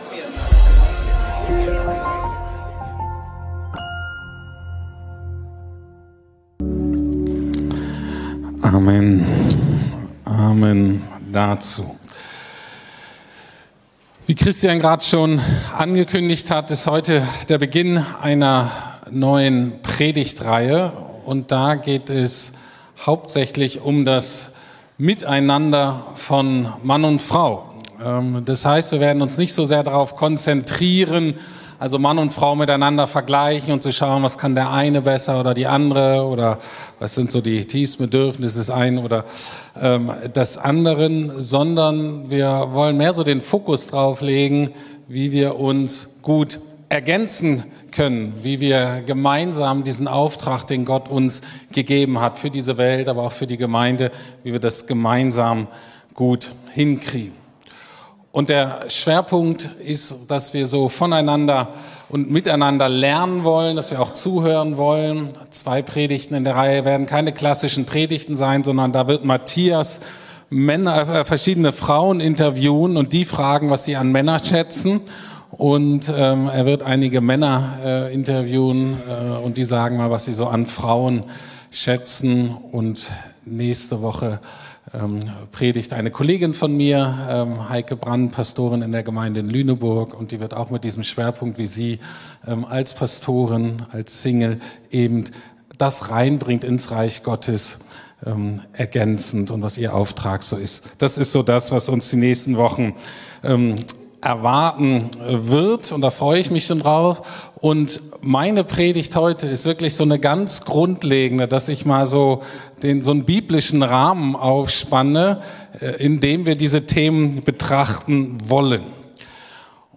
Anmerkung: Die Audioversion enthält aus Copyrightgründen nur einen Teil des Gottesdienstes Der Beitrag 16.2.2025 — „Die Bedeutung der Taufe“ erschien zuerst auf Evangelisch-Freikirchliche Gemeinde …